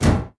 ladder4.wav